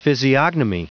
Prononciation du mot physiognomy en anglais (fichier audio)
Prononciation du mot : physiognomy